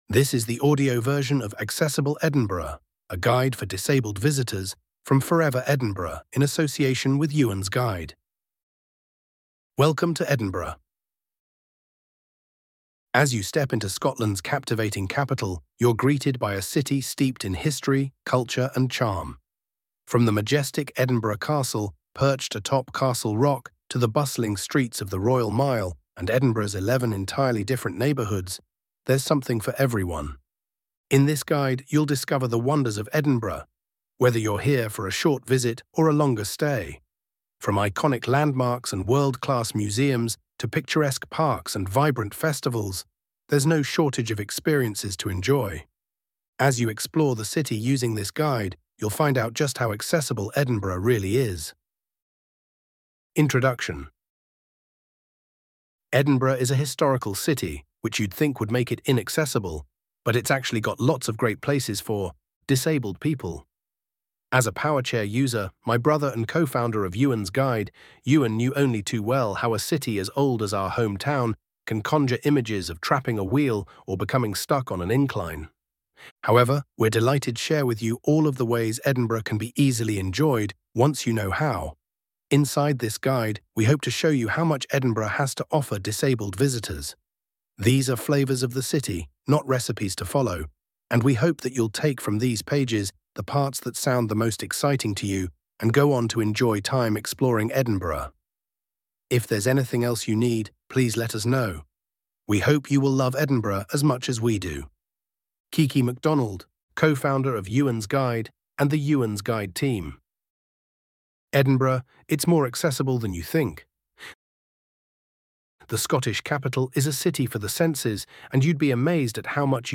Audio Described
Edinburgh-Accessible-Highlights-Guide-Audio-Description-April-25.mp3